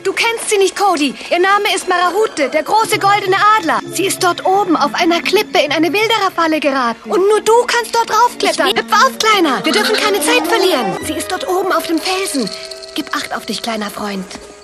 Im Original wurden beide Rollen von der selben Person eingesprochen. Klingt hier ebenso.